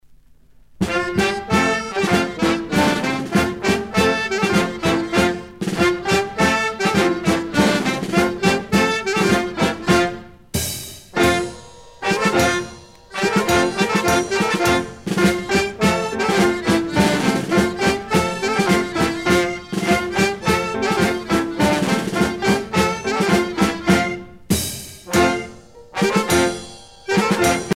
danse : polka lapin
Pièce musicale éditée